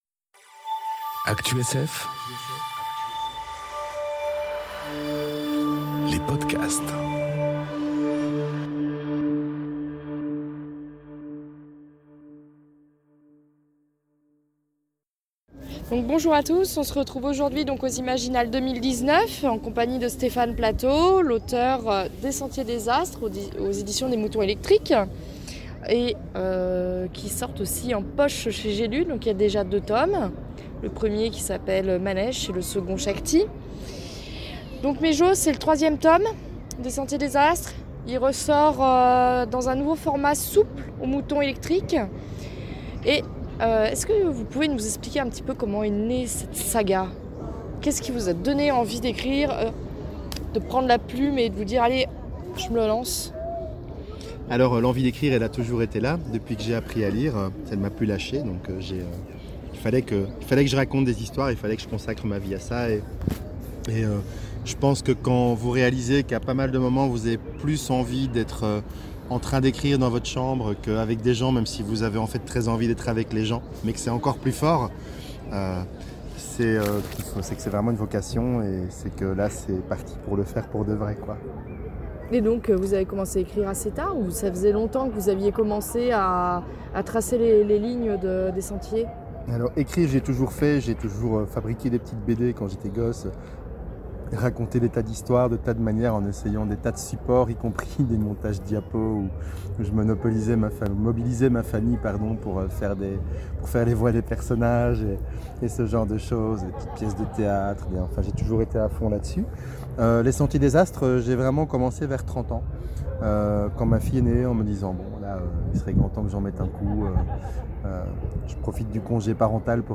A l'occasion des Imaginales 2019, qui se sont déroulées du 23 au 26 mai à Epinal, découvrez une interview